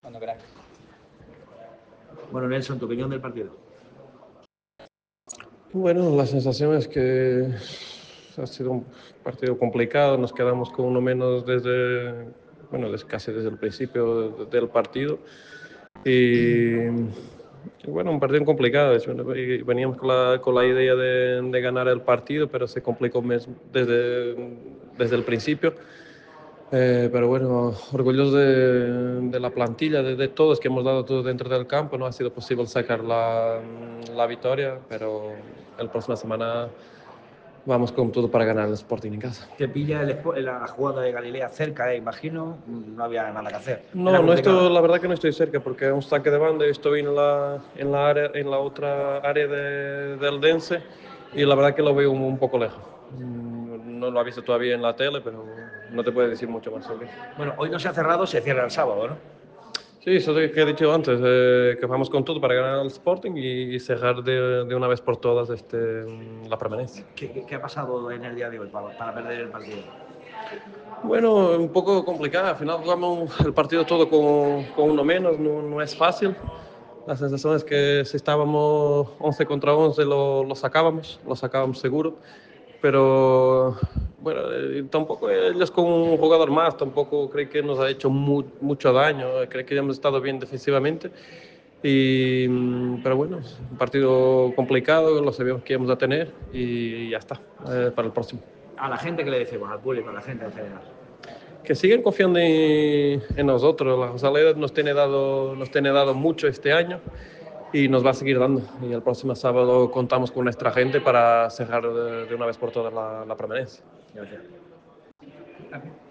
ha comparecido ante los medios en zona mixta